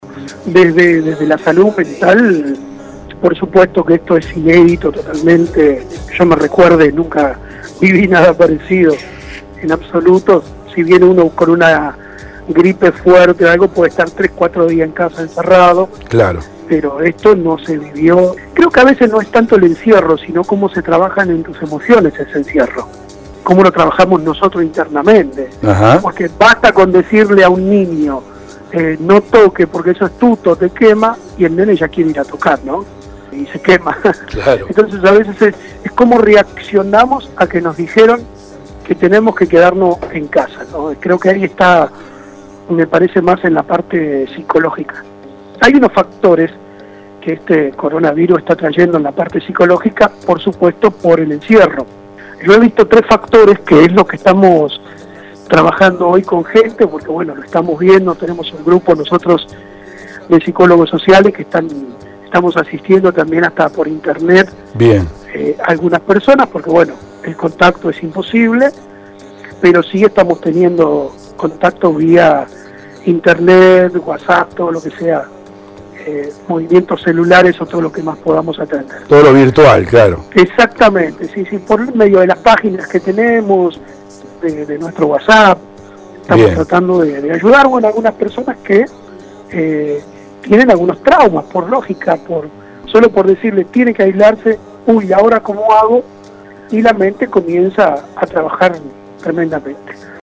EGRESADOS DE LA ESCUELA EN ZARATE